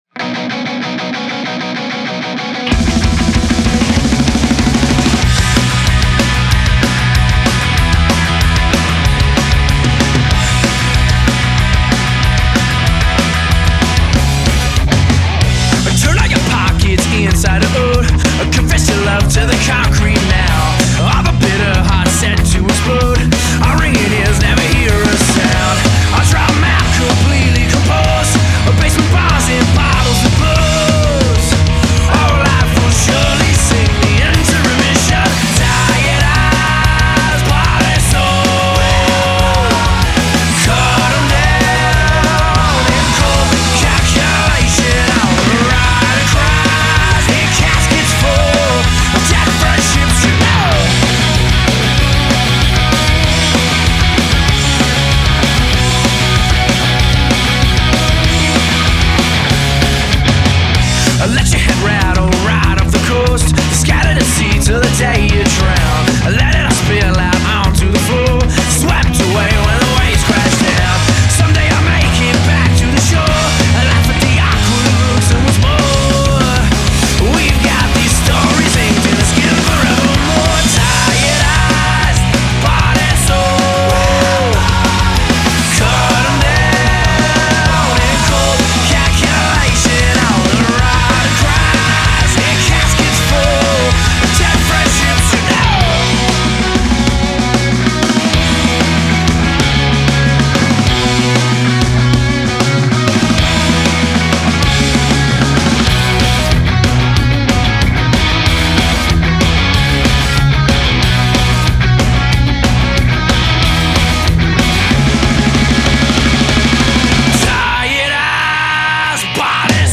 I can get behind some straight ahead modern punk.